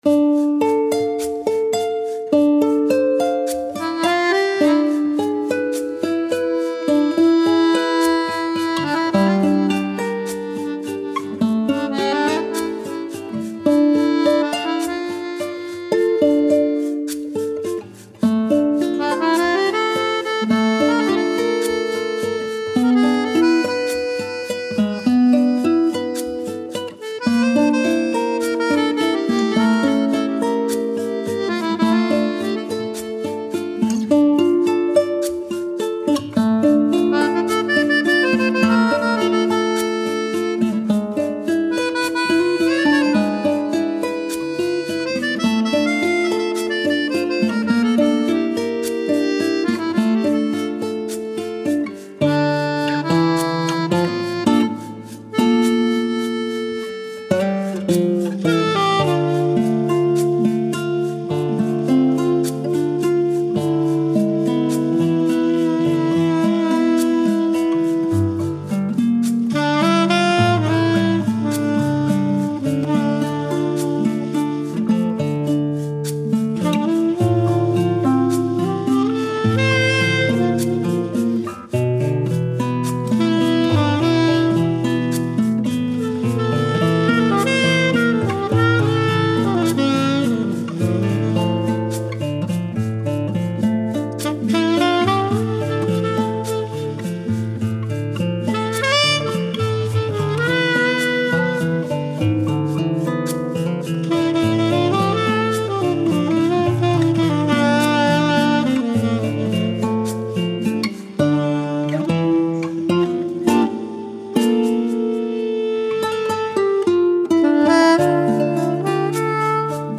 Gitarren
Violoncello
Saxophon